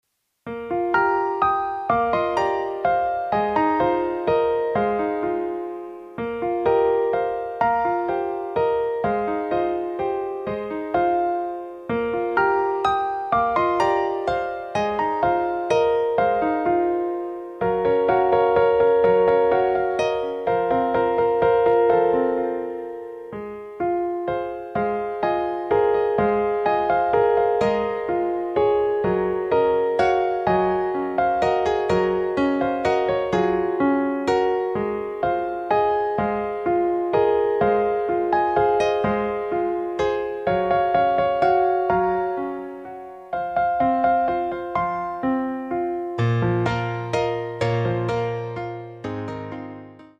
不思議な響きのするピアノの曲を目指して作ってみました。